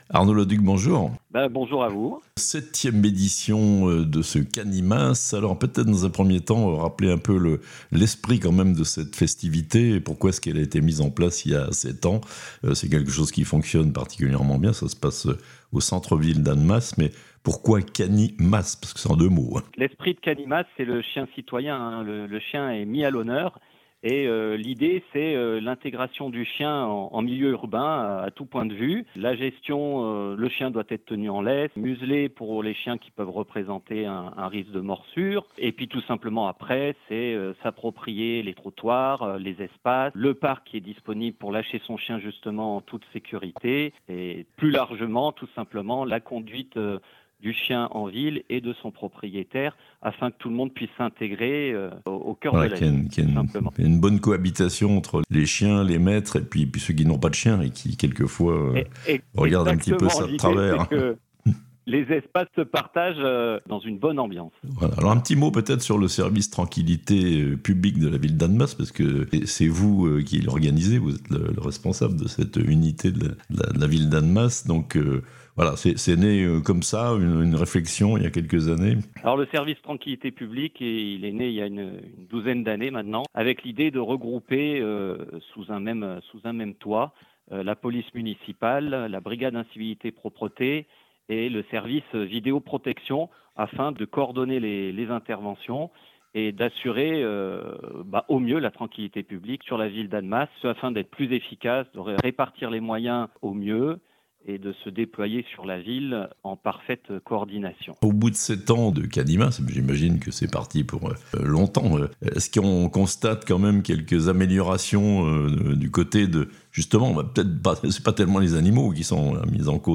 au micro de La Radio Plus pour le présentation de Cani'Masse 2024.